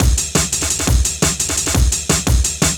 cw_amen07_172.wav